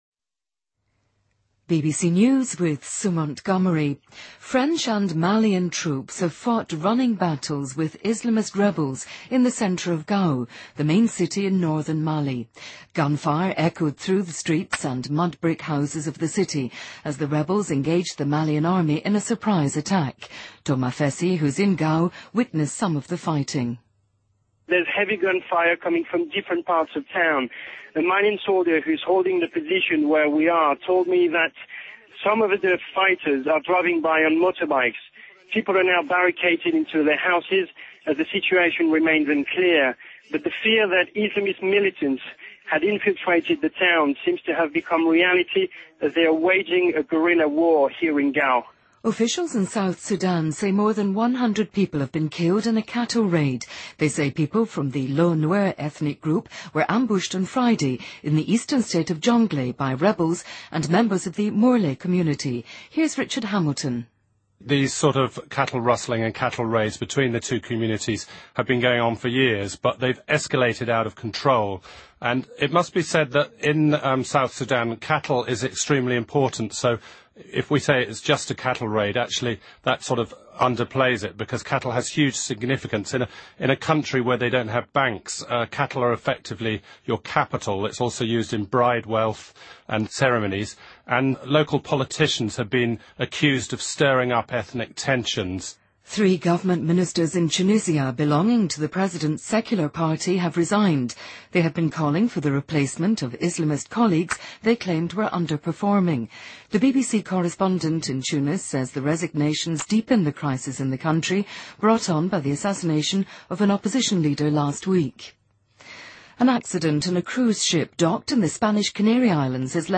BBC news,伦敦举行第66届英国电影电视艺术学院奖颁奖典礼